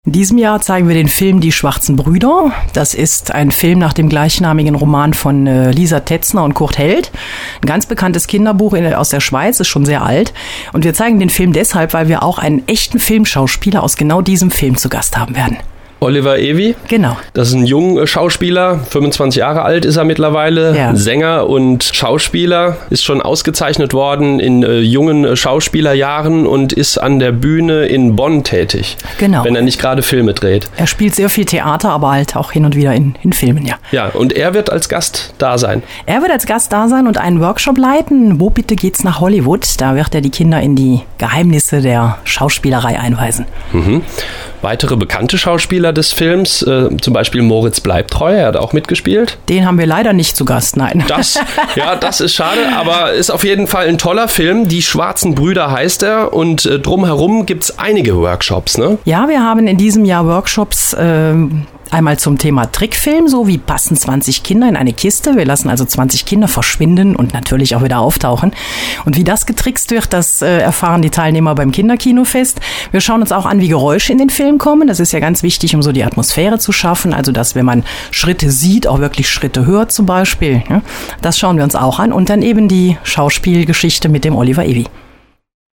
Radio Contact